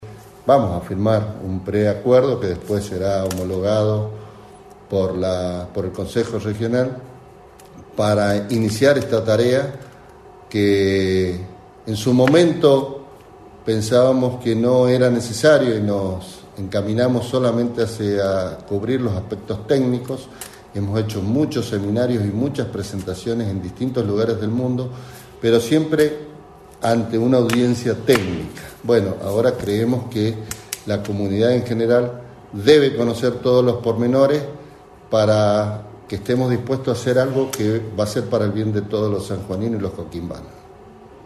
Por su parte, el ministro Ortiz Andino agregó que
Ministro-Obras-Publicas-San-Juan-Julio-Ortiz-Andino.mp3